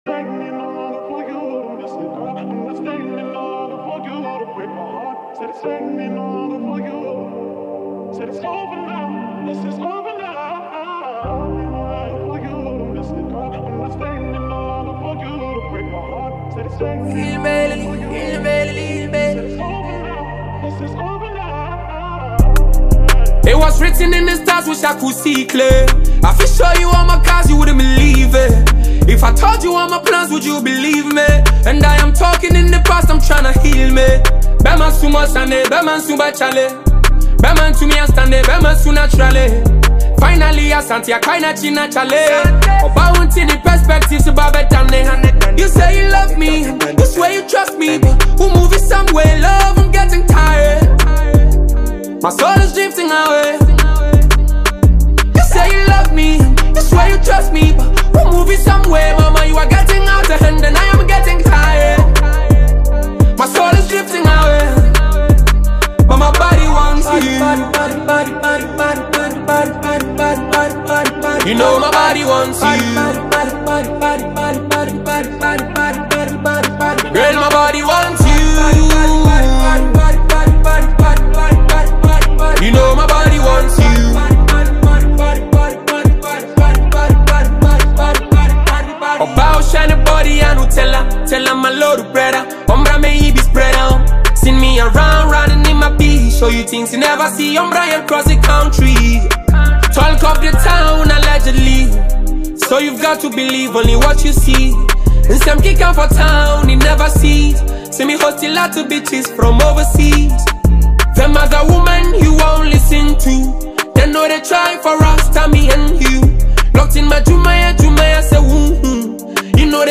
Ghanaian rap artist